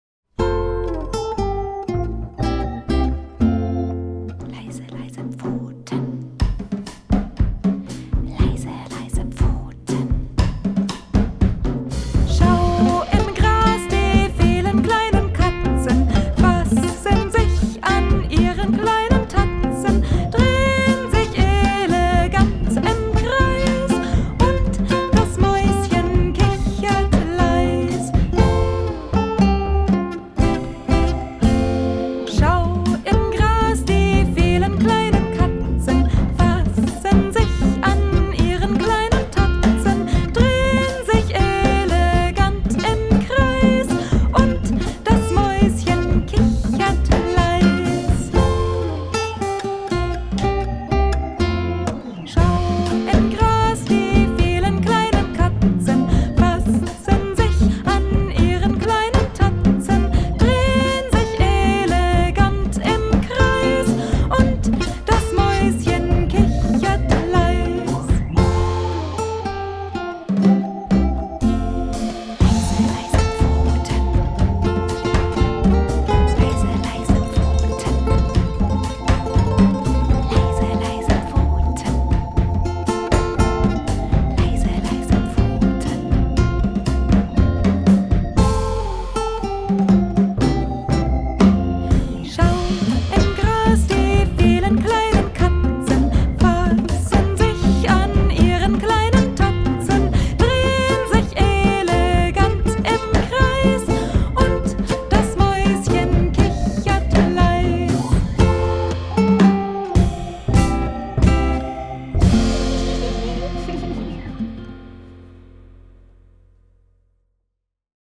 Einspielung des Liedes